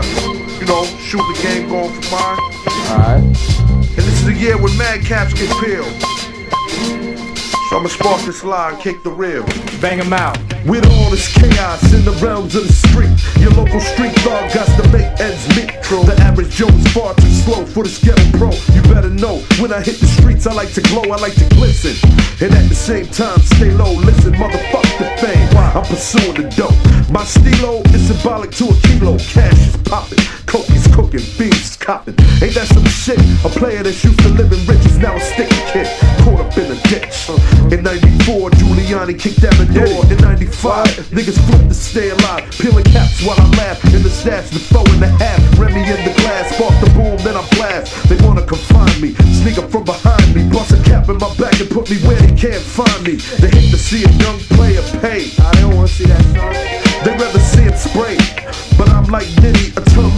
ハープシコード入りでボッサに仕上げた